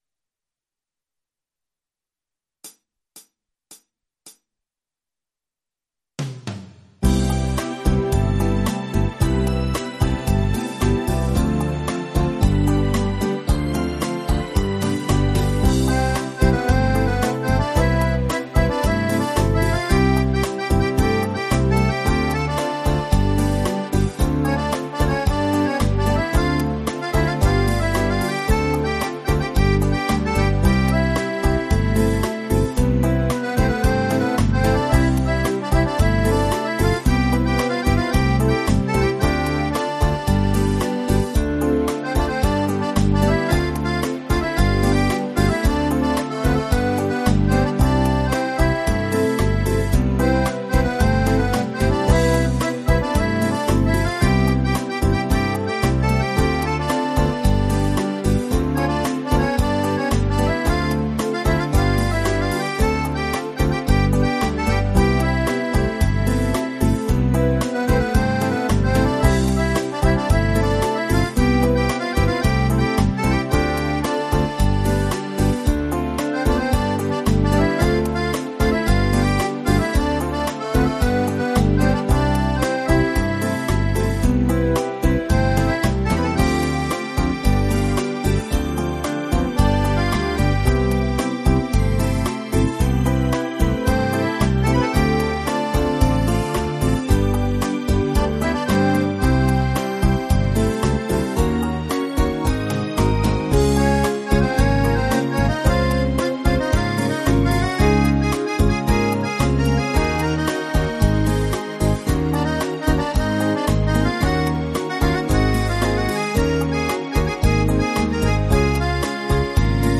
versão instrumental multipista